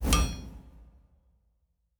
pgs/Assets/Audio/Fantasy Interface Sounds/Blacksmith 03.wav at master
Blacksmith 03.wav